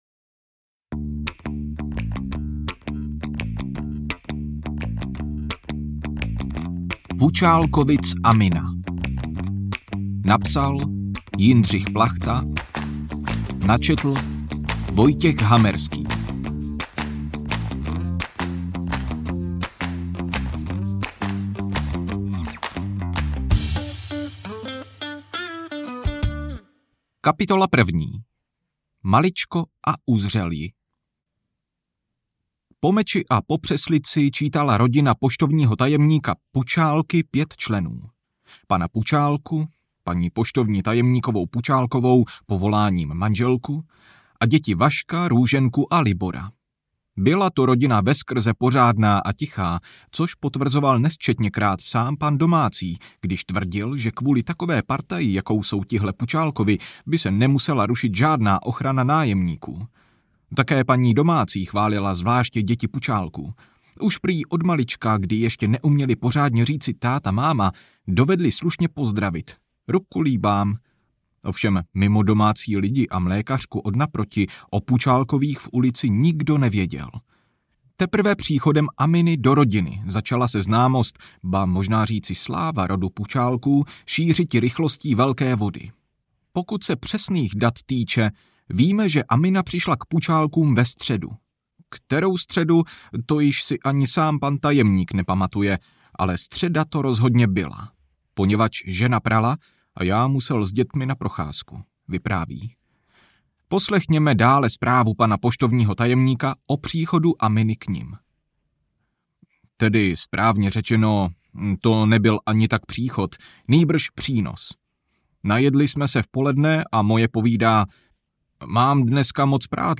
Pučálkovic Amina audiokniha
Ukázka z knihy